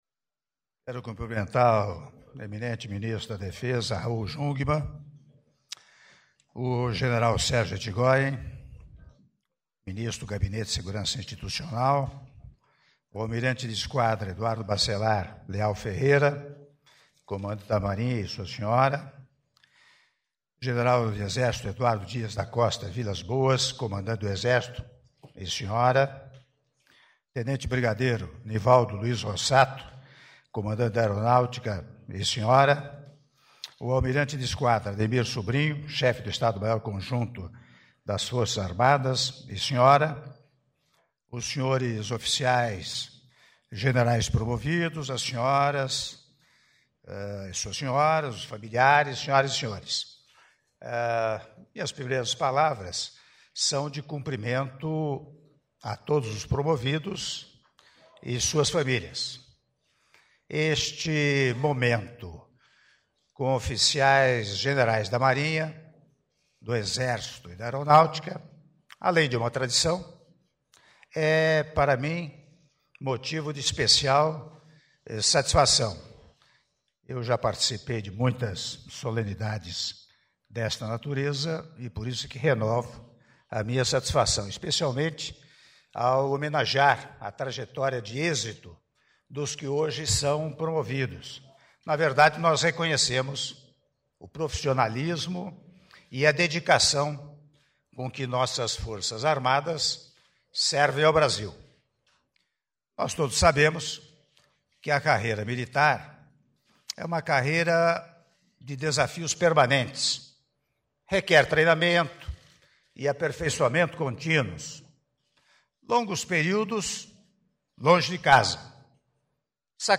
Áudio do discurso do Presidente da República, Michel Temer, durante cerimônia de apresentação dos Oficiais-Generais promovidos - Brasília/DF (7min22s) — Biblioteca